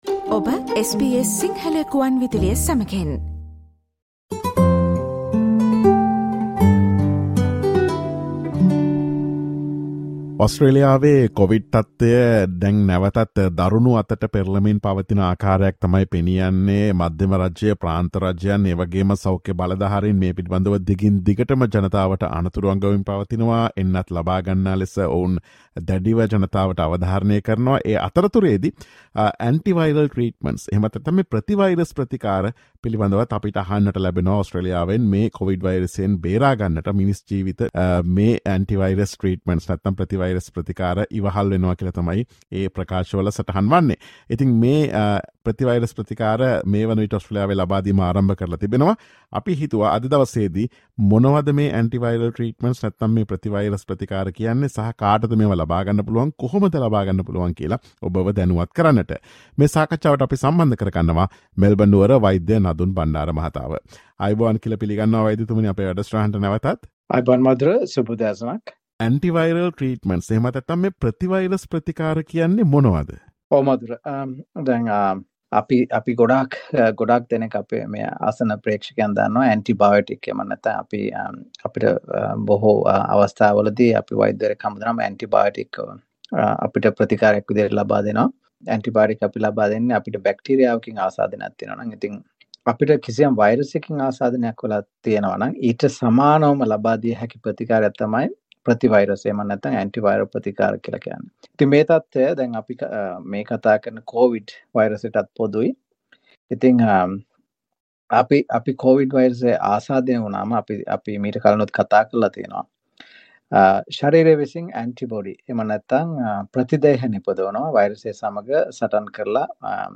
ඔස්ට්‍රේලියාවේ ලබාගත හැකි COVID-19 ප්‍රතිවෛරස් ප්‍රතිකාර සහ ඒවා ලබාගත හැකි ආකාරය පිළිබඳ SBS සිංහල ගුවන් විදුලිය සිදුකළ සාකච්ඡාවට සවන් දෙන්න.